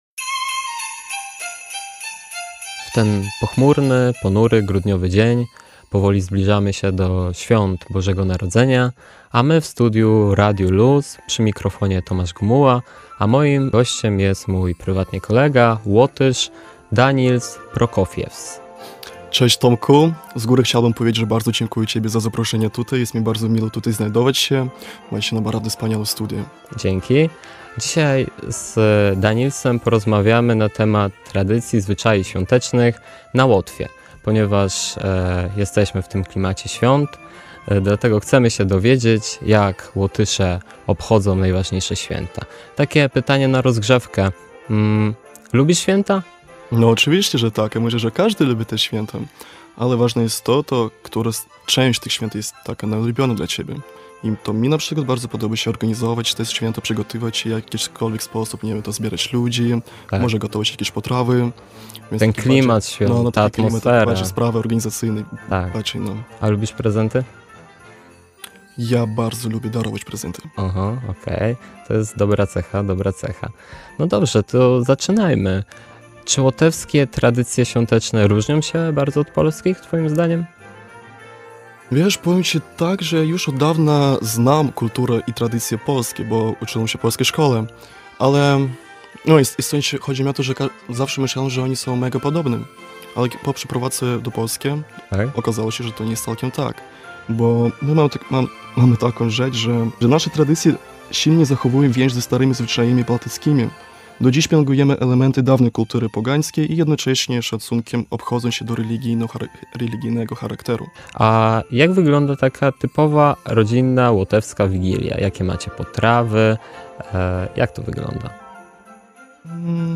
Wywiad-swieta2-z-podkladem.mp3